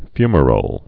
(fymə-rōl)